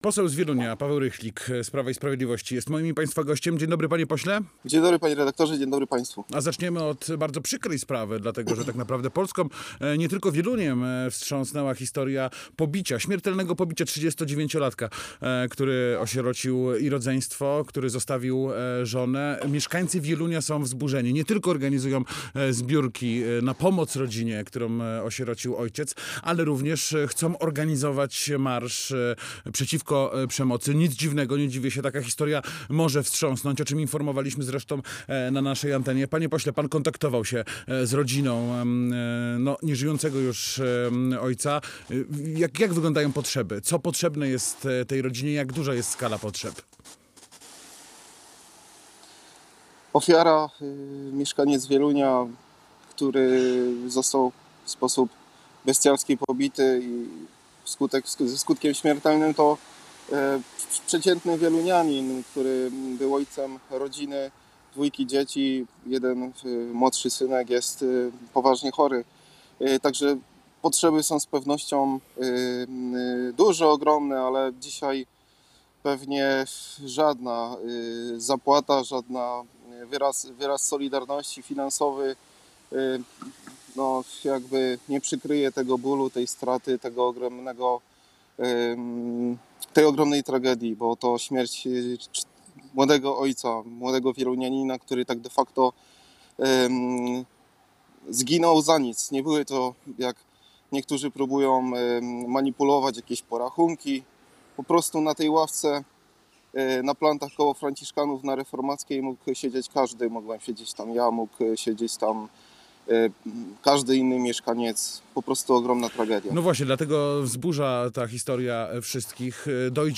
Gościem Rozmowy Dnia był poseł Prawa i Sprawiedliwości Paweł Rychlik.